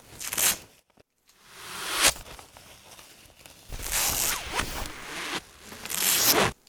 bandage_01.ogg